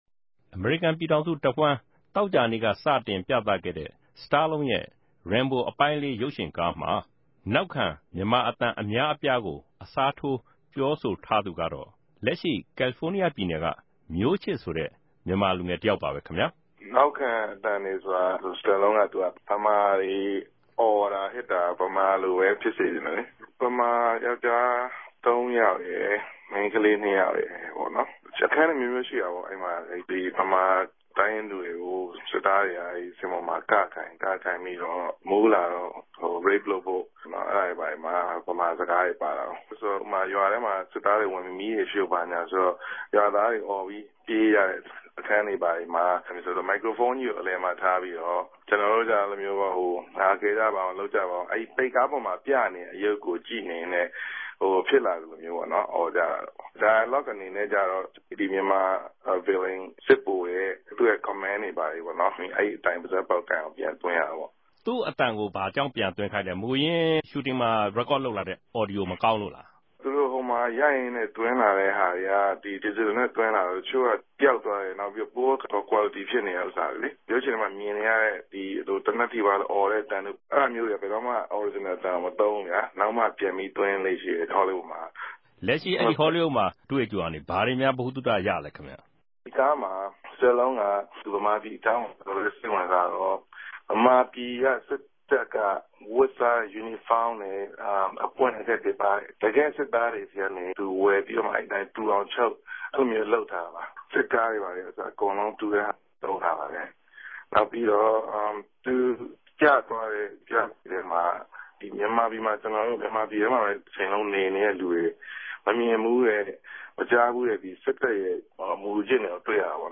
ဒီရုပ်ရြင်ကားမြာ ပၝဝင်တဲ့ ူမန်မာအသံ အတော်မဵားမဵားကို ဟောလီးဝုဒ် စတူဒီယိုမြာ သရုပ်ဆောင်တြေ ကိုယ်စားေူပာဆိုပေးခဲ့တဲ့ လူငယ်တယောက်နဲႛ ရုပ်ရြင်ကို ပထမဦးဆုံးနေႛမြာ ုကည့်ရခြဲ့သူတခဵိြႛကို